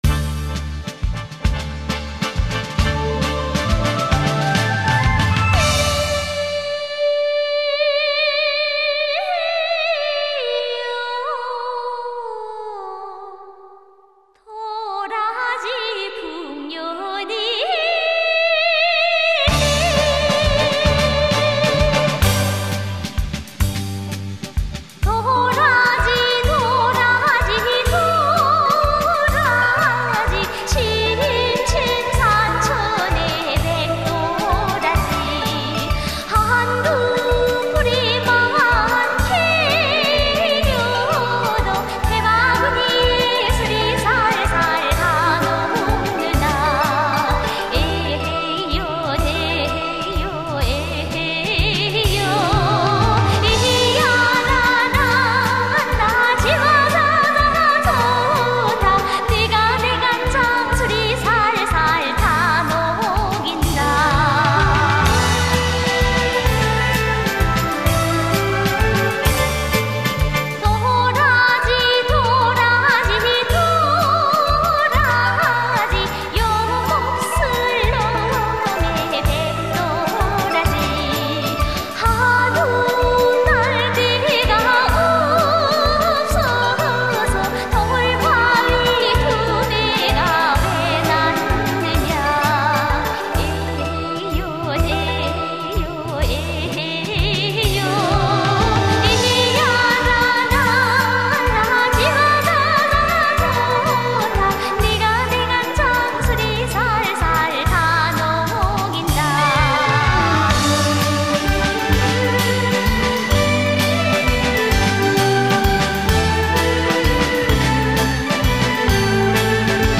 Народные песни